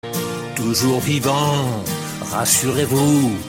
spatial sound
use_totem.ogg